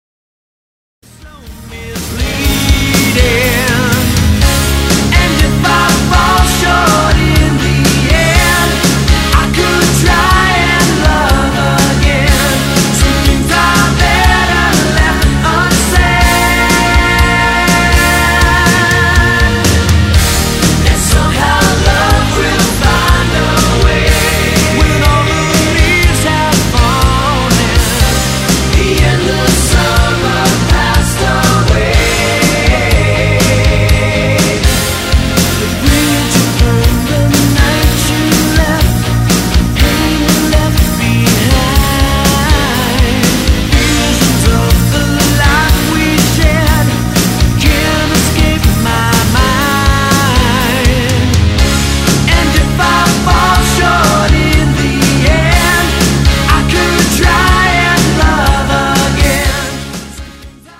Guitar & Lead Vocals
Guitar, Keyboards & Vocals
Drums, Percussion & Vocals
Bass & Vocals
Recorded & Mixed in Denver, Colorado June 94 to Feb 95.
classic melodic rock albums